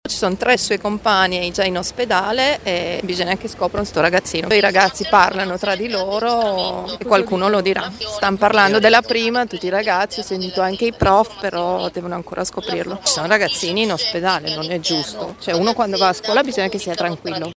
Sono stati contattati i genitori che hanno raggiunto i figli a scuola, sentiamo una mamma